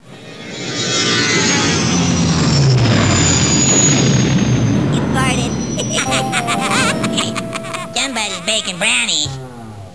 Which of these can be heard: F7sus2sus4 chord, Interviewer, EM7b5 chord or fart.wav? fart.wav